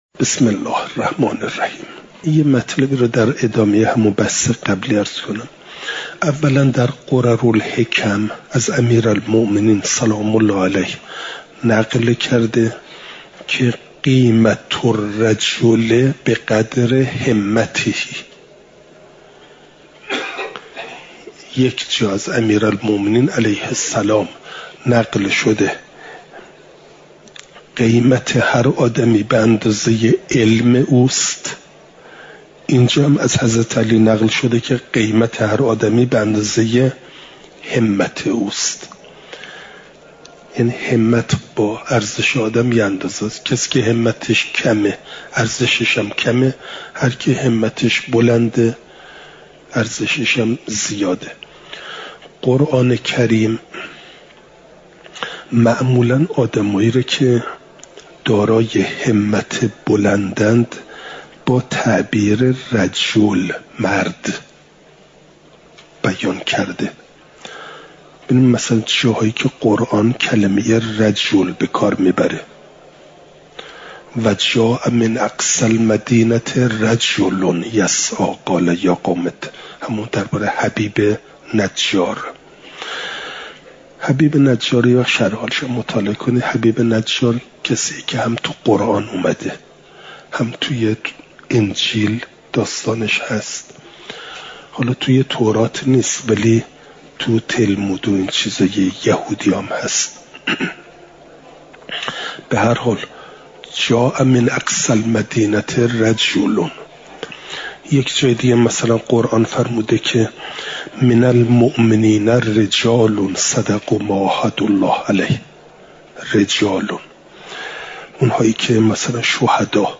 چهارشنبه ۲۳ آبانماه ۱۴۰۳، حرم مطهر حضرت معصومه سلام ﷲ علیها